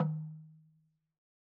LogDrumHi_MedM_v2_rr2_Sum.wav